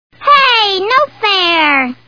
The Simpsons [Lisa] Cartoon TV Show Sound Bites